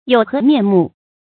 有何面目 注音： ㄧㄡˇ ㄏㄜˊ ㄇㄧㄢˋ ㄇㄨˋ 讀音讀法： 意思解釋： 指沒有臉見人。